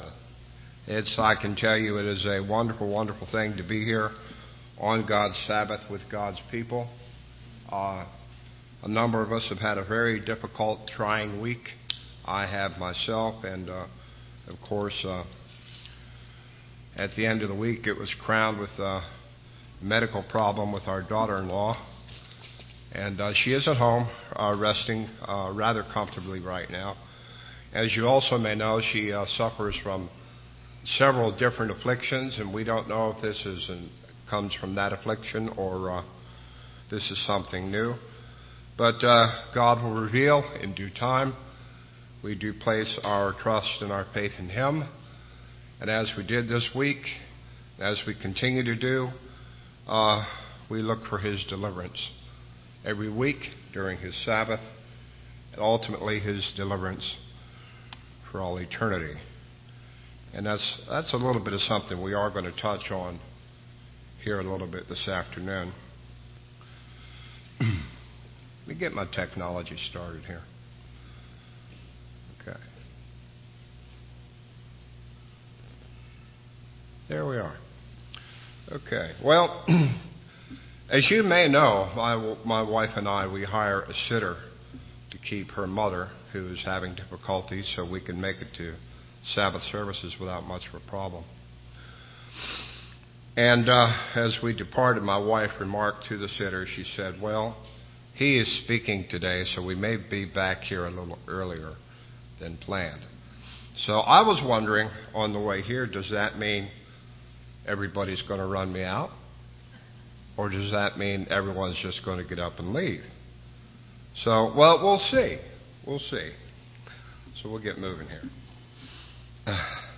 Given in Rome, GA